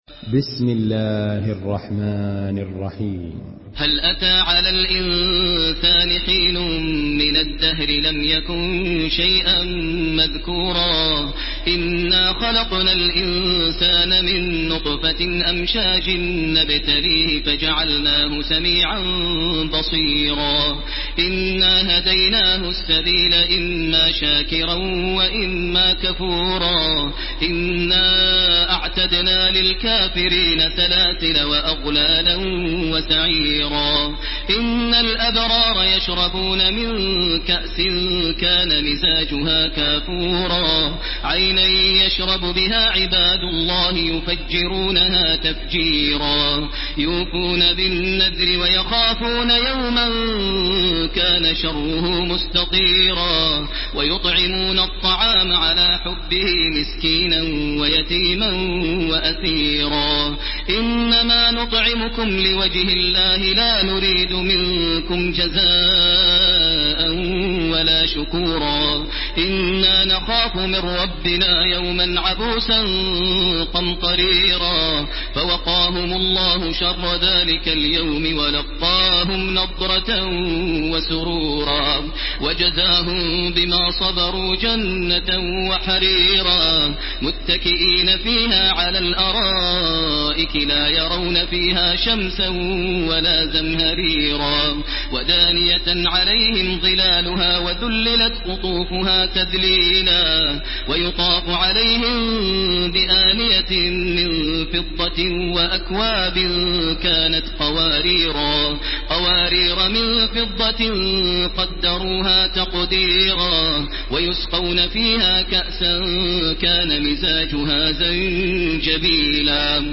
Surah الإنسان MP3 in the Voice of تراويح الحرم المكي 1430 in حفص Narration
مرتل